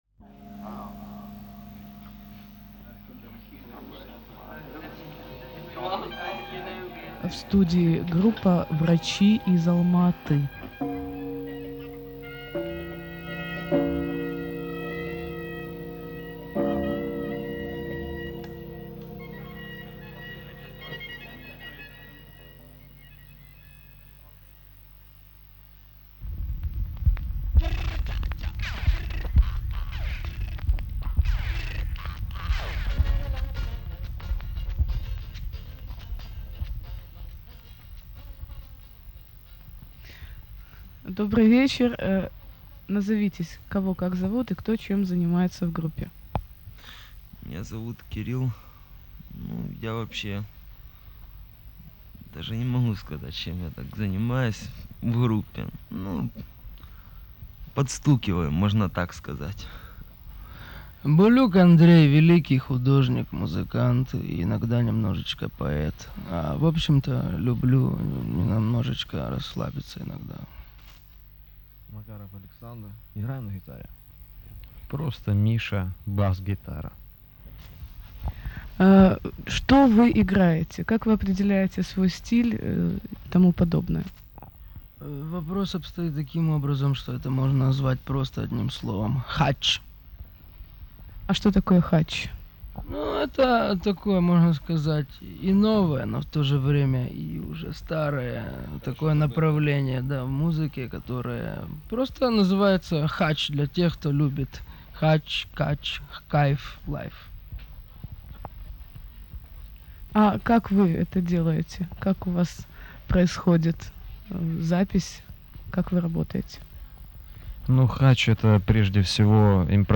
Русский рок Рок музыка Альтернативный рок